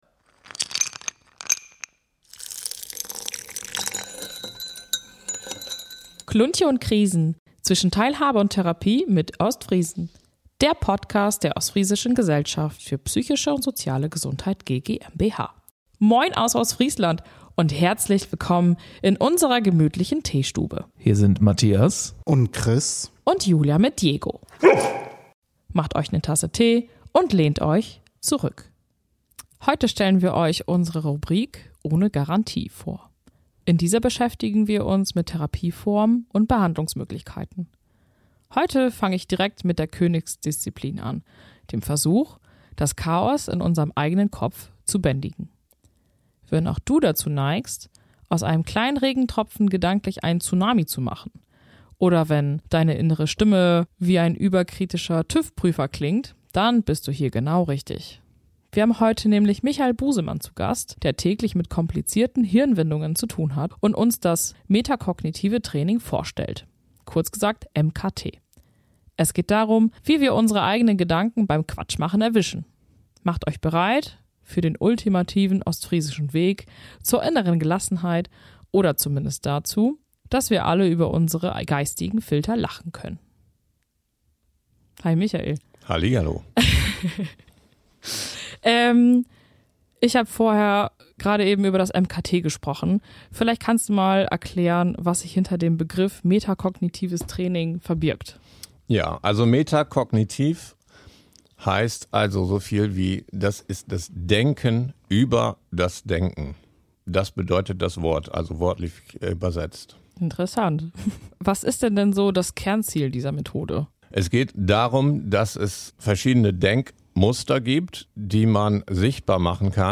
Wir sprechen offen über den Zugang zum Training, den Ablauf im Einzel- oder Gruppensetting und natürlich auch darüber, wo die Grenzen dieser Methode liegen.